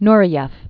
(nrĭ-yĕf, n-rā-), Rudolf Hametovich 1938-1993.